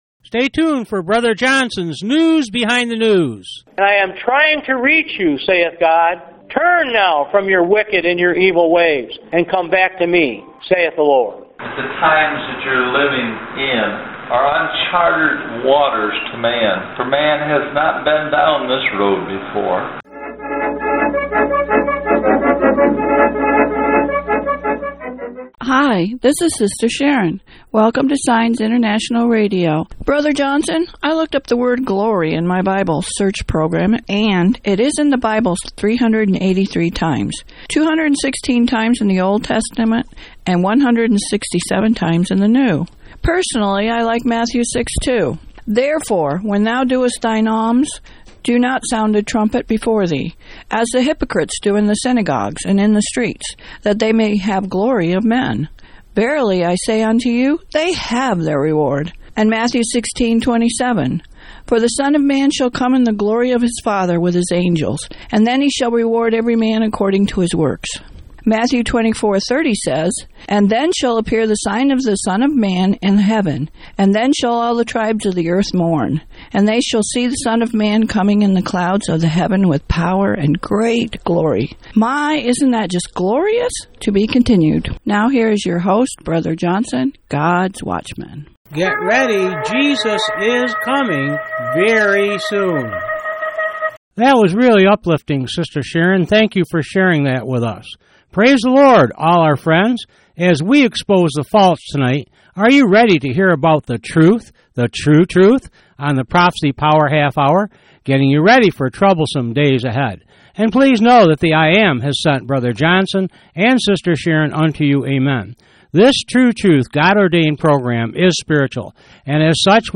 Message Details: Wednesday March 6, 2013 Prophecy Power half hour news